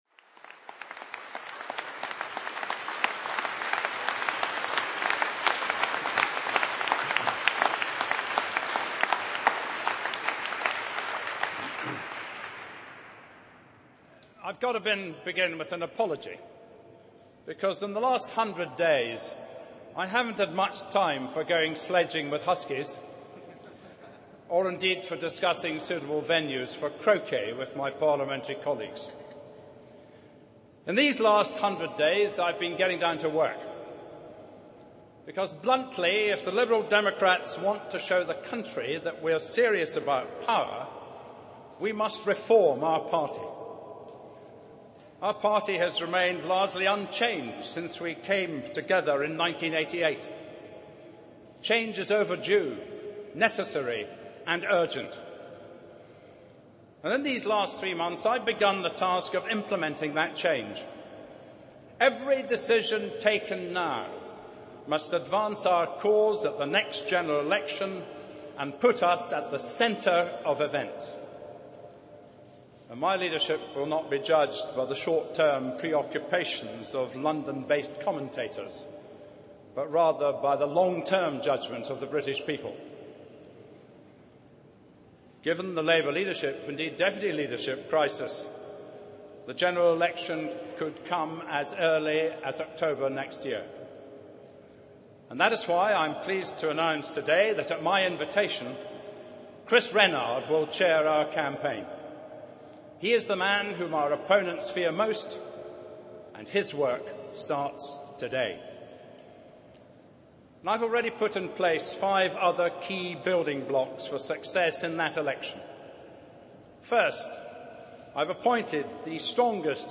Download and listen to Ming’s 100 day speech (MP3, 10,263kB)